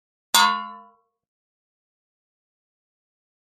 Metal Pan Ping Hit, Type 6